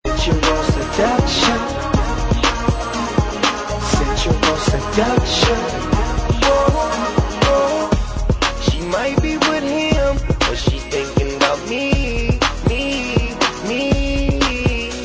• Rap Ringtones